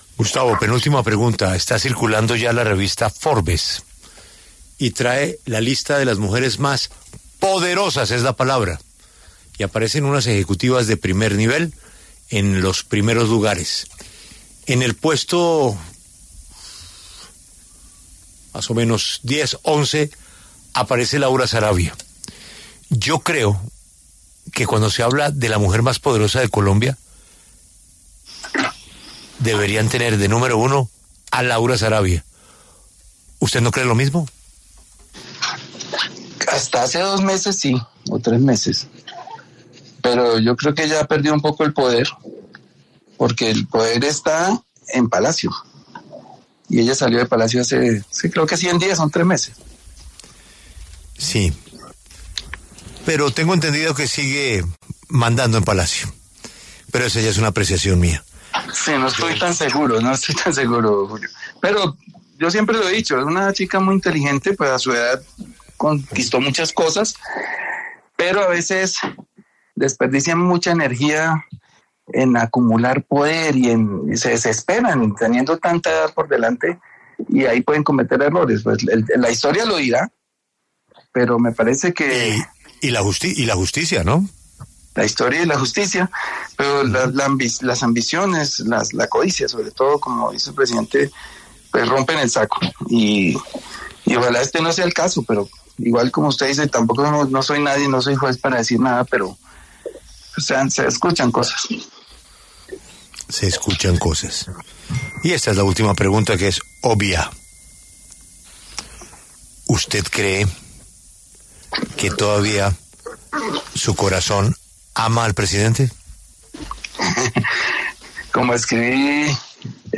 Con el reciente informe de la Revista Forbes sobre las mujeres más poderosas de Colombia, Gustavo Bolívar habló en La W sobre Laura Sarabia, canciller de Colombia.